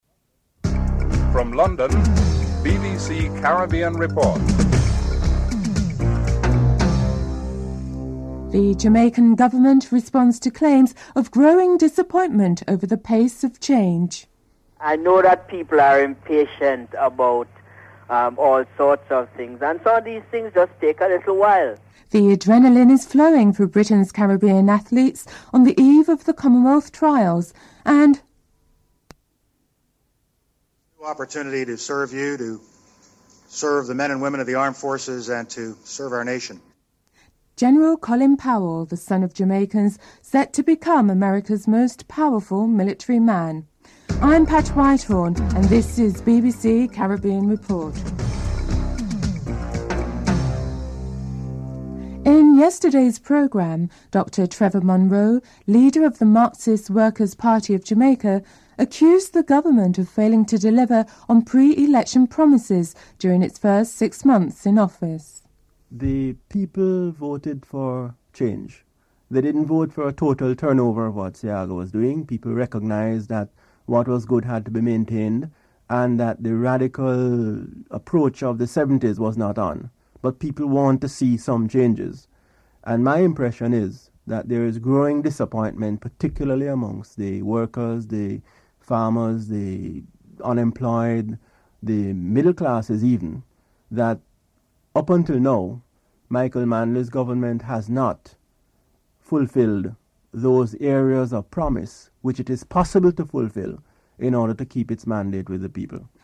1. Headlines (00:00-01:30)
4. Financial news (07:14-08:41)